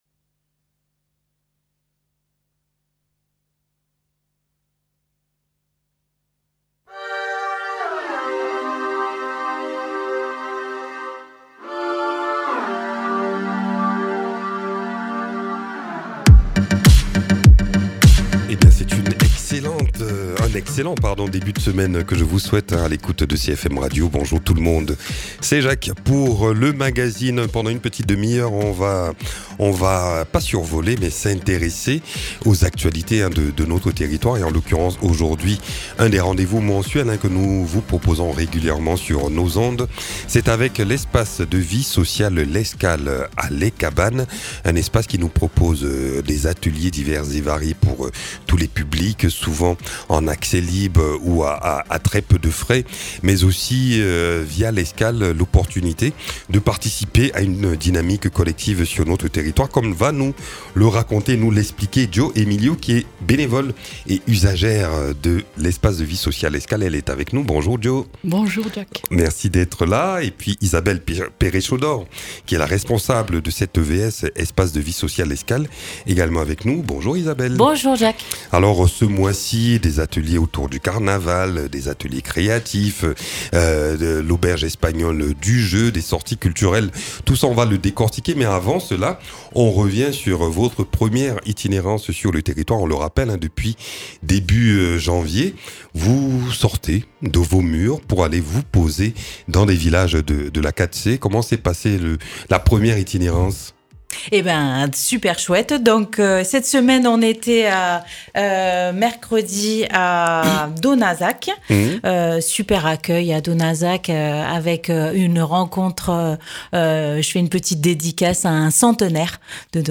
Et puis, une bénévole et usagère de l’escale témoigne de son investissement dans cette structure.